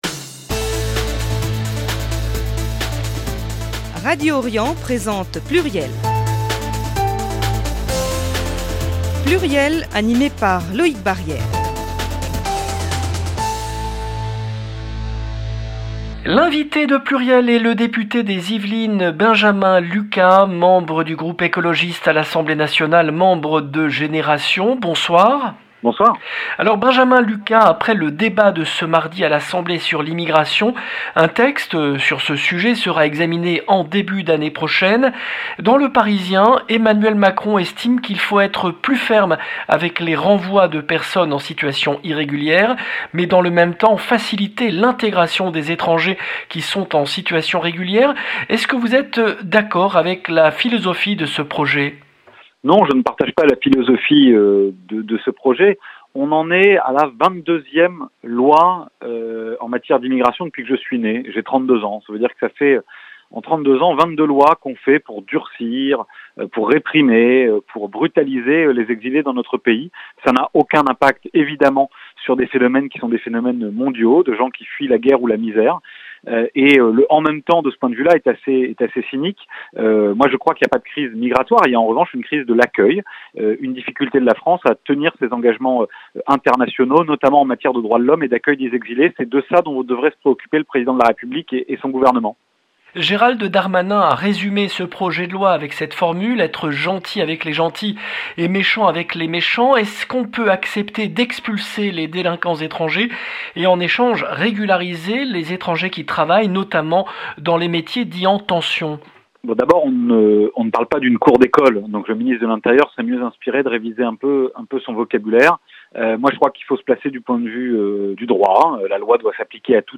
Benjamin LUCAS, député des Yvelines